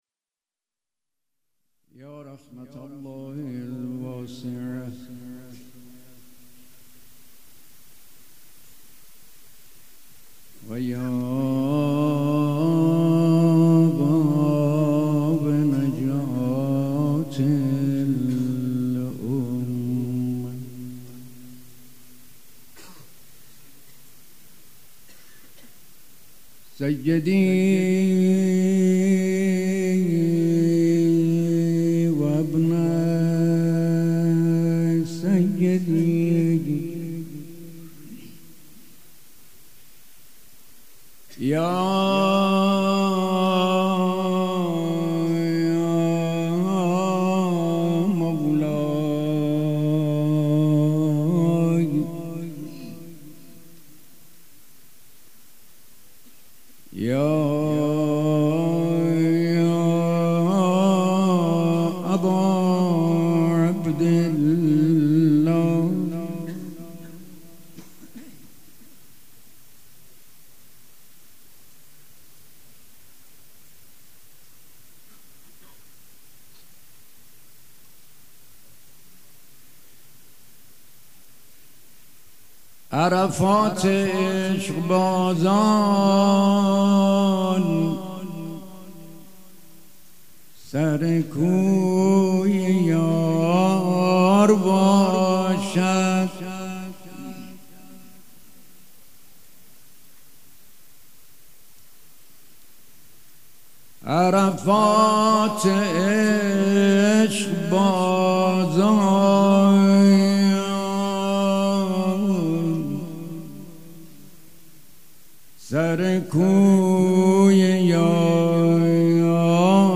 پیش منبر
مراسم شهادت حضرت ام البنین سلام الله علیها سخنران : حاج آقای قرائتی 1شنبه 21اسفند ۱۳۹۵ هیأت ریحانة الحسین سلام الله علیها